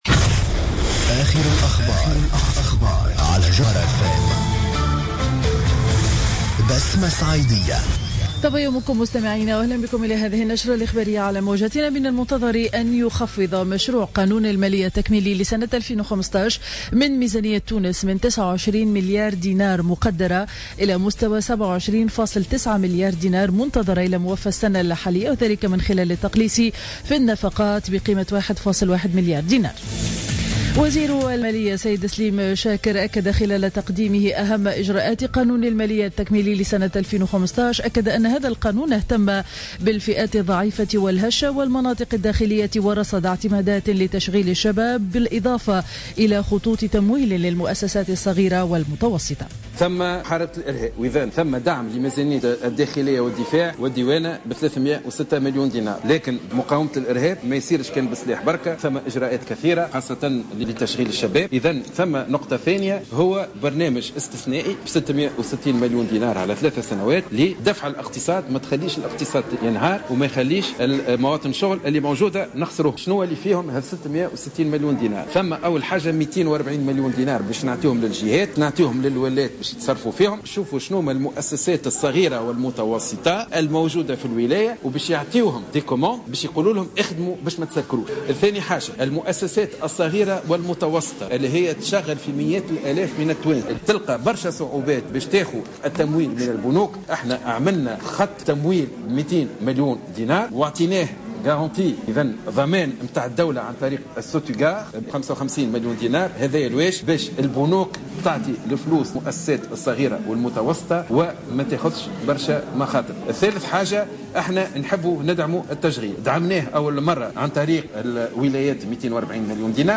نشرة أخبار السابعة صباحا ليوم الجمعة 31 جويلية 2015